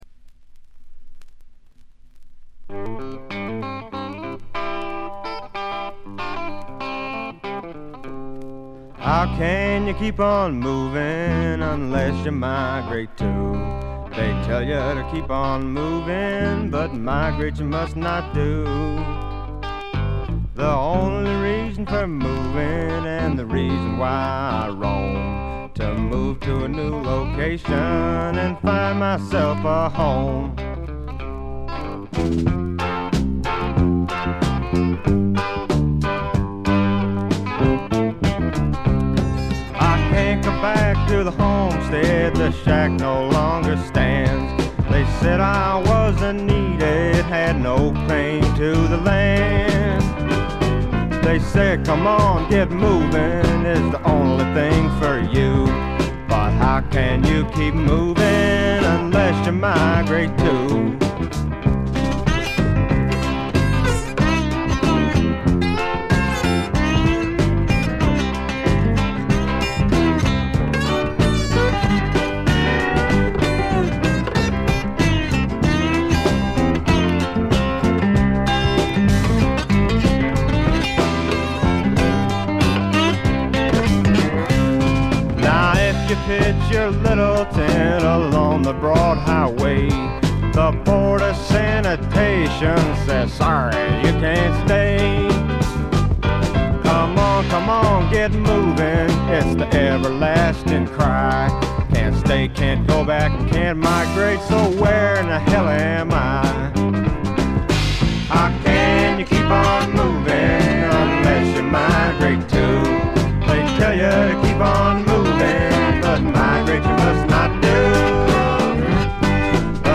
軽微なチリプチがわずかに出る程度。
試聴曲は現品からの取り込み音源です。
guitar, bass, mandolin, vocals